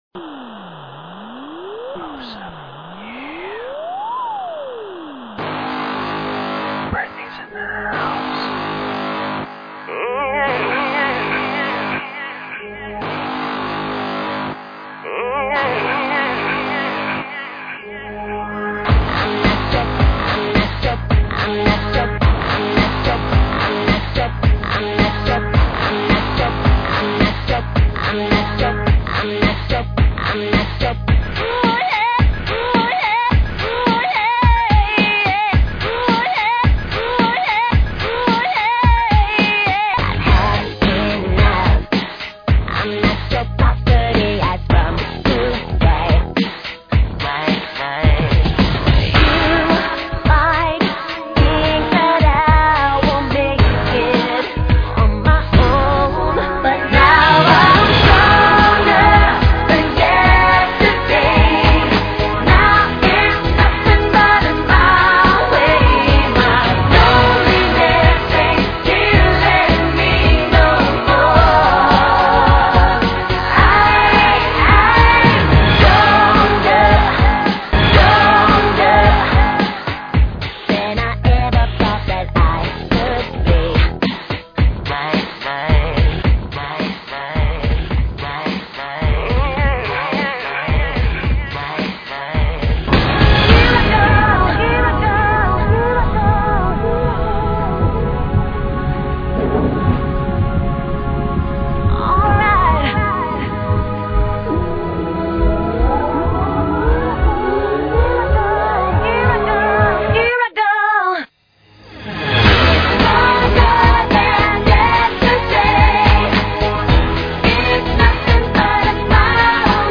.MP3    (MPEG 2.5 layer 3, 24KB per second, 11,025 Hz, Mono)
Pop